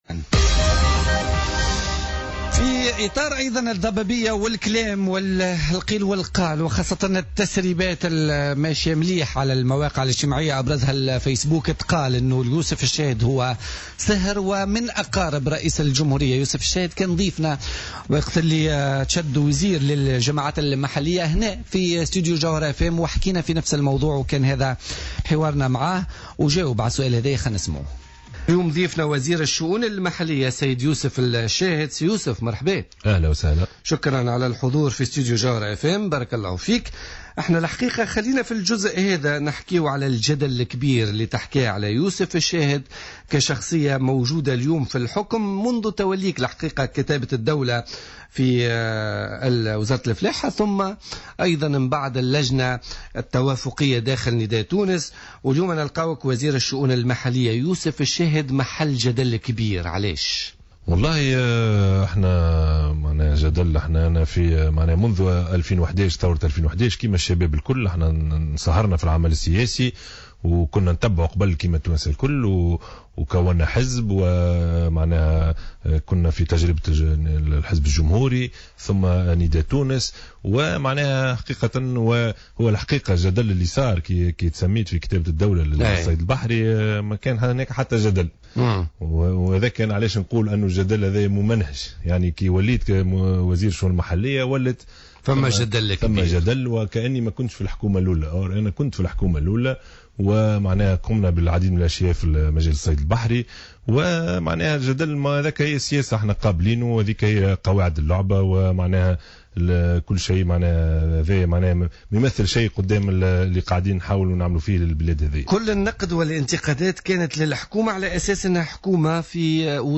وكان يوسف الشاهد قد أكد خلال استضافة سابقة له على الجوهرة "اف ام" عقب تسميته في كتابة الدولة أن ما يروج بخصوص قرابة تربطه برئيس الجمهورية لا أساس له من الصحة مشيرا إلى أن علاقته الوحيدة بالباجي قايد السبسي هي العلاقة الحزبية .